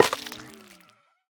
Minecraft Version Minecraft Version snapshot Latest Release | Latest Snapshot snapshot / assets / minecraft / sounds / block / sculk_catalyst / break4.ogg Compare With Compare With Latest Release | Latest Snapshot